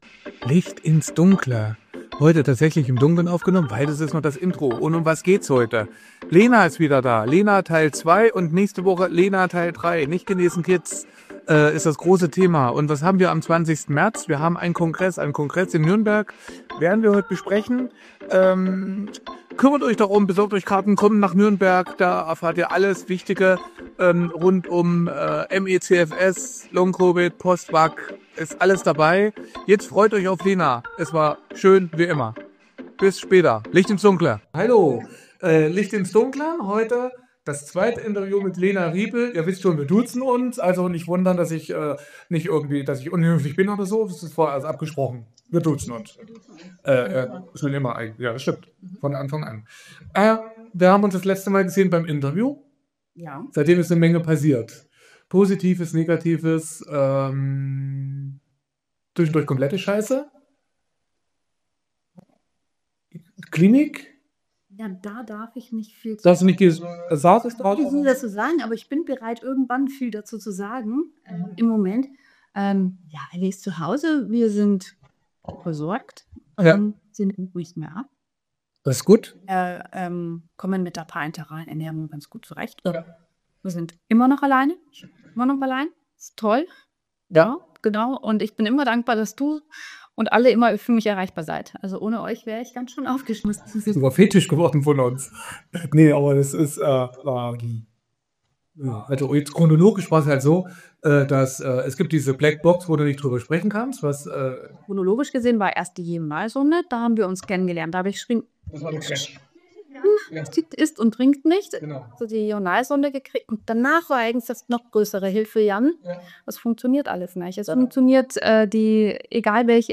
Zwischen Hoffnung und Hilflosigkeit: Leben mit ME/CFS | Interview